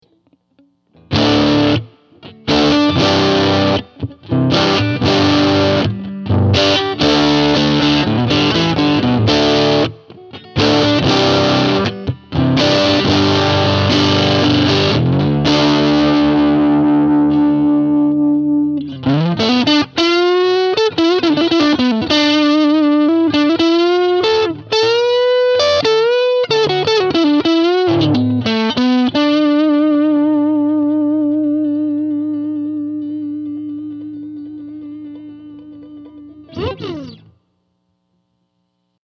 All clips were recorded using my American Deluxe Strat, straight into my VHT Special 6 combo where the speaker resides. Note that I close-miked the amp and had it cranked!
What you hear in these clips is pretty much the raw sound with just a touch of reverb with the dirty clips (<10% wet).
Dirty (Neck pickup)